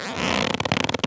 foley_leather_stretch_couch_chair_01.wav